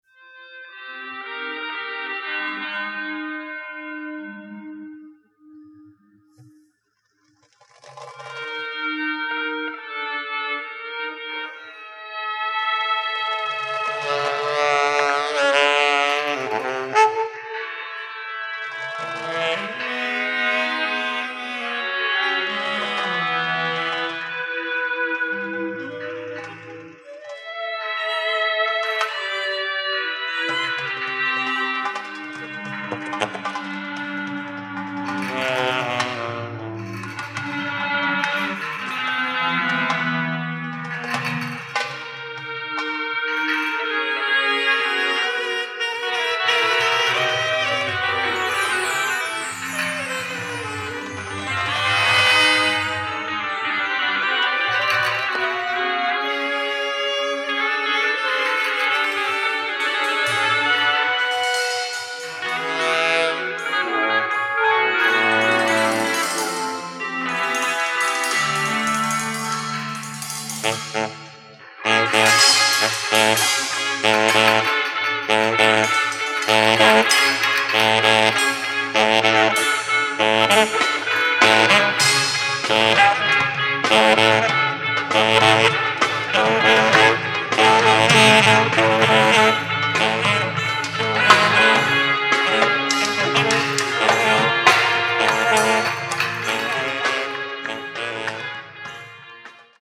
地球外イメージかき立てる、霊感山勘みなぎる謎めいた即興空間。
キーワード：霊性　地球外　即興